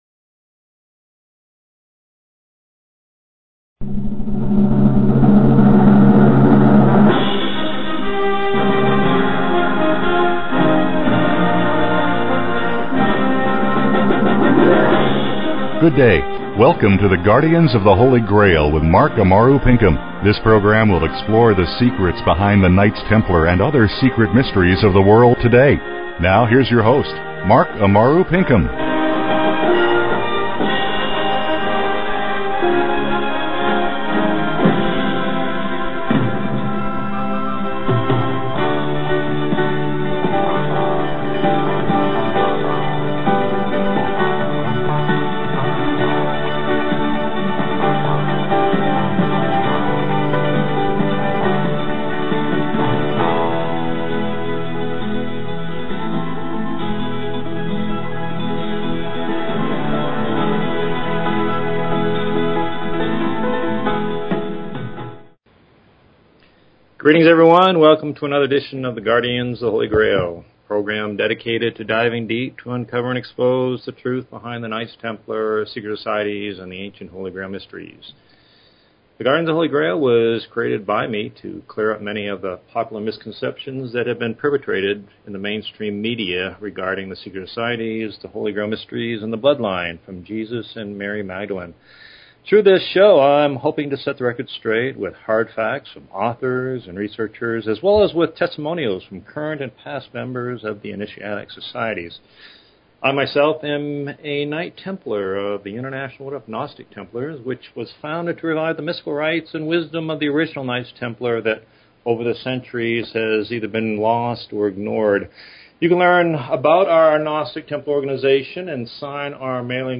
Talk Show Episode, Audio Podcast, The_Guardians_of_the_Holy_Grail and Courtesy of BBS Radio on , show guests , about , categorized as